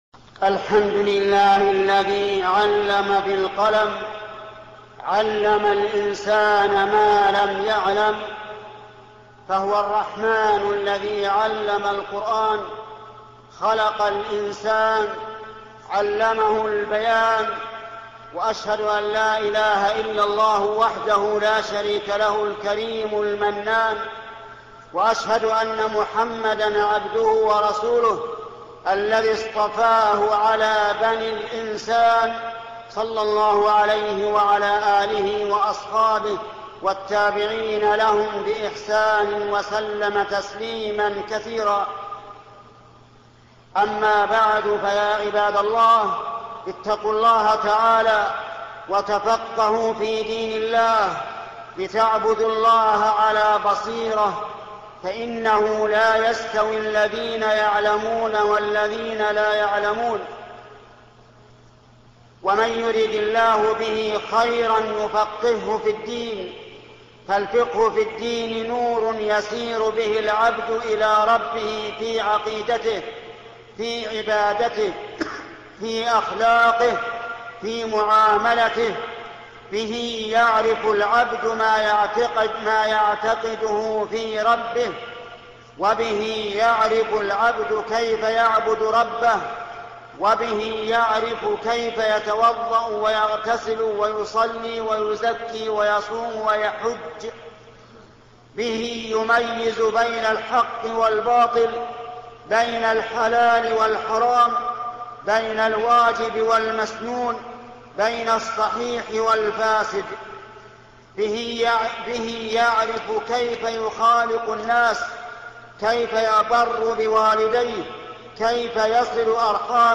خطب الجمعة - الشيخ محمد بن صالح العثيمين